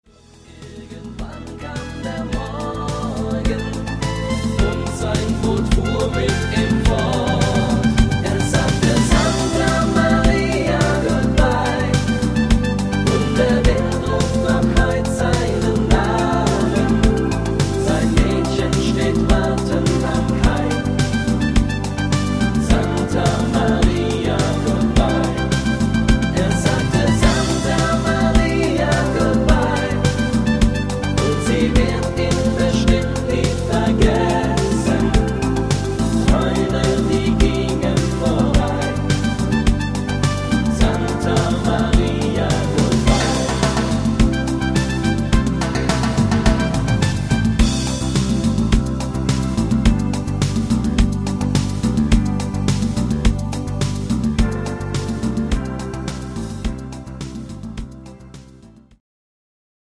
Rhythmus  Discofox
Art  Schlager 90er, Deutsch